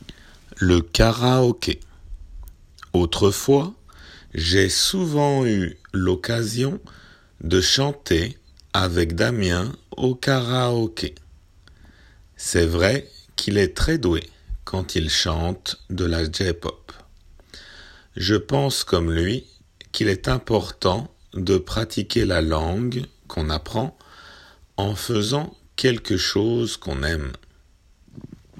普通の速さで